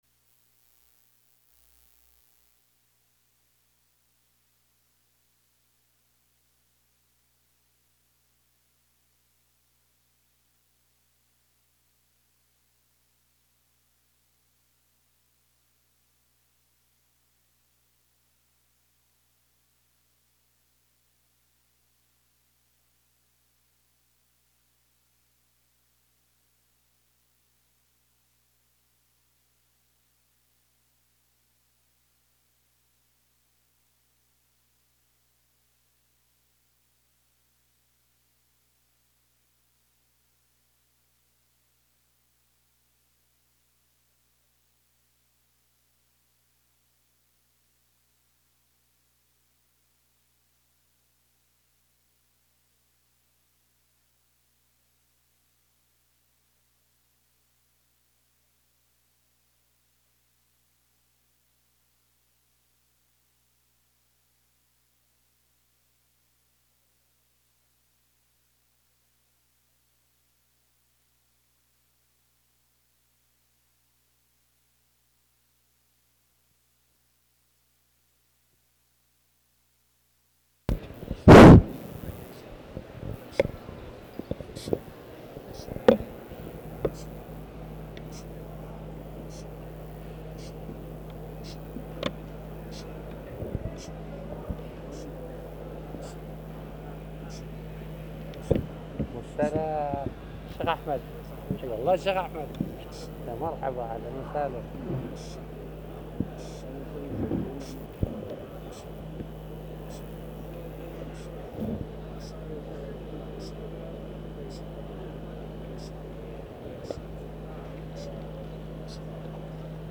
تاريخ النشر ٢٩ صفر ١٤٤٠ هـ المكان: المسجد الحرام الشيخ